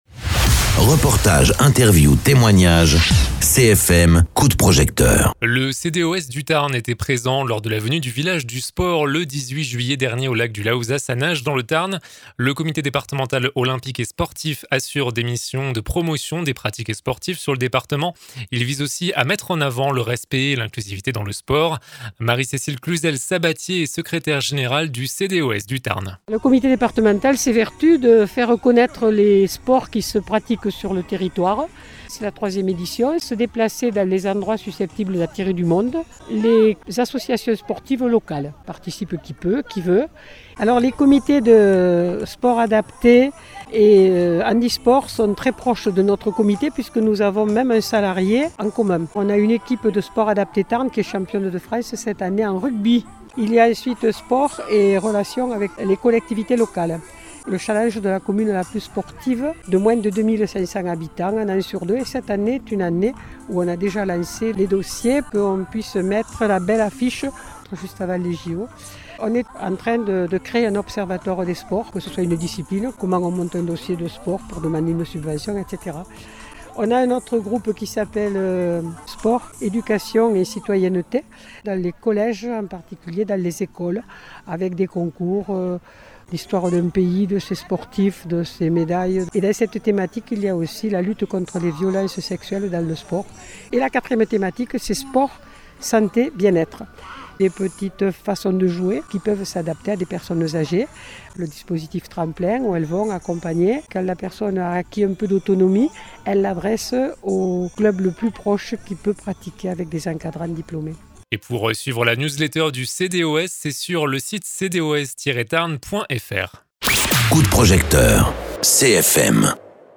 Interviews
Un entretien avec le comité départemental olympique et sportif du Tarn, lors du village du sport, à Nages dans le Tarn, le 18 juillet dernier.